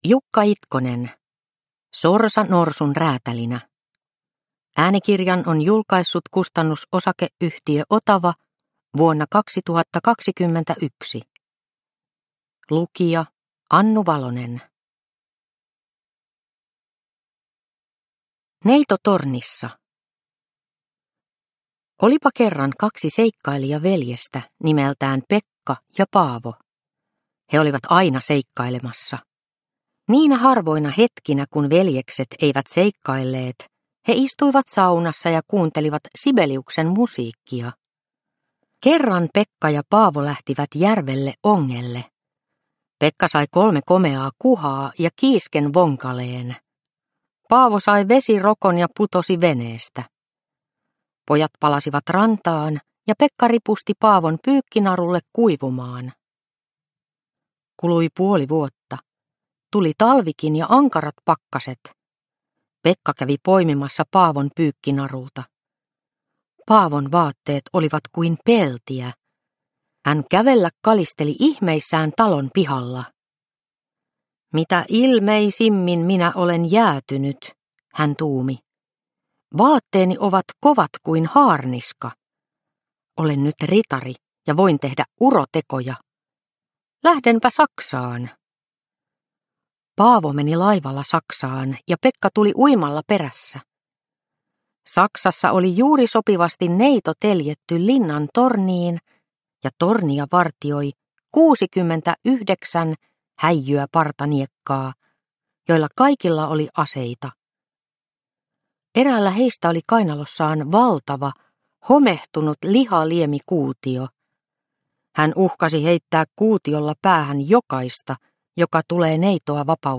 Sorsa norsun räätälinä – Ljudbok – Laddas ner